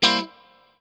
CHORD 7   AD.wav